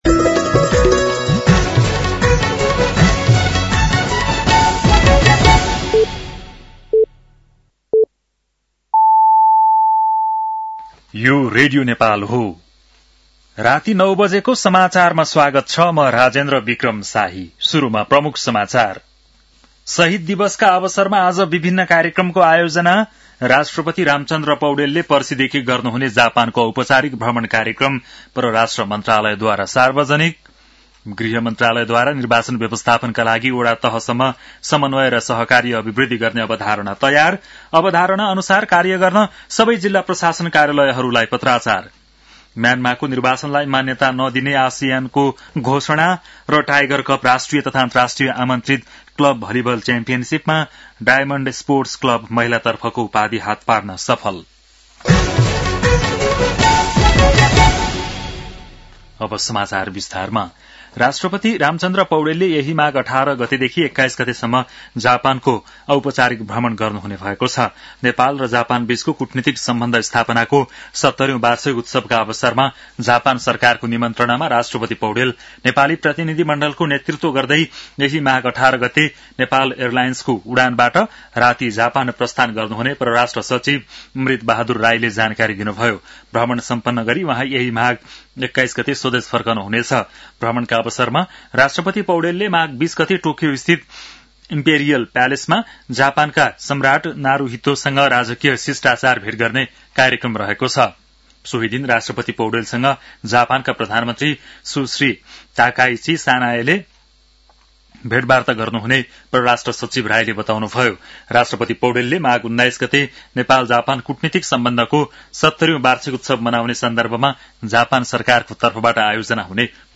बेलुकी ९ बजेको नेपाली समाचार : १६ माघ , २०८२
9-pm-nepali-news-10-16.mp3